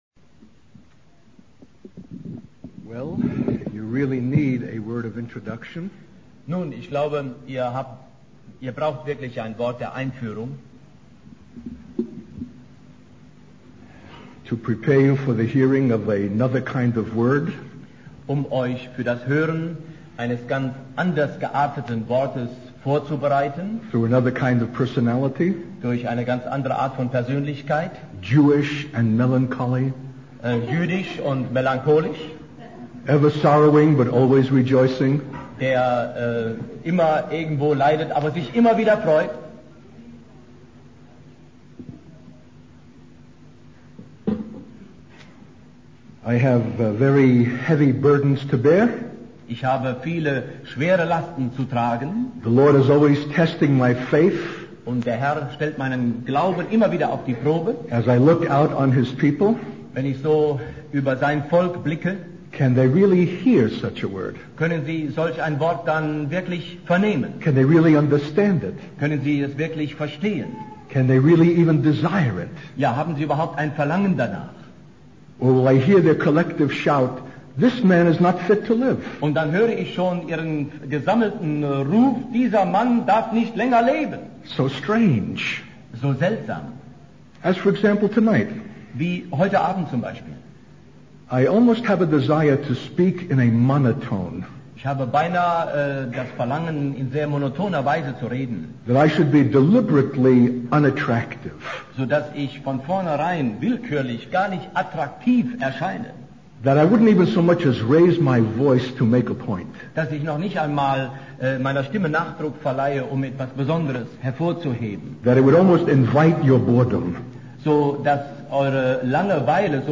In this sermon, the speaker begins by praying for a resurrection event through the spoken word. He emphasizes the need for the church to reach a place of apostolic and prophetic maturity.